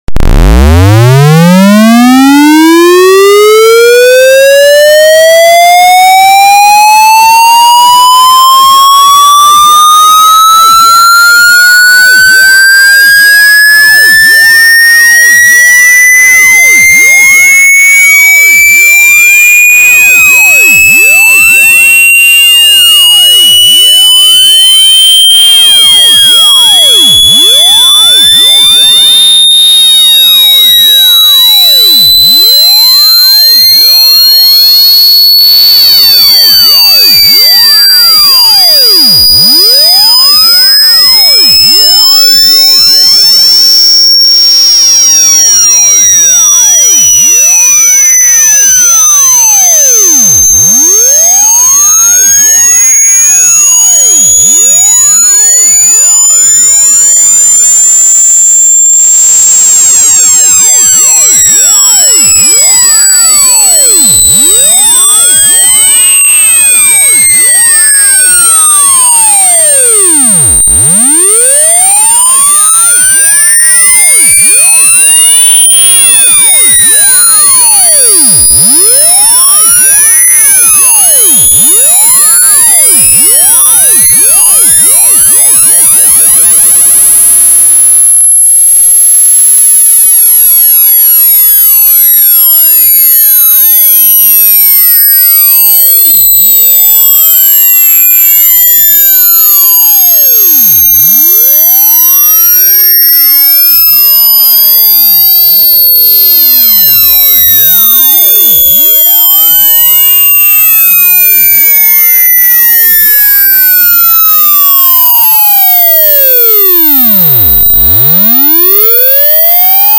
1Hz 15000Hz Tone Sweep sound effects free download